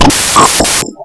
ship_explosion3.wav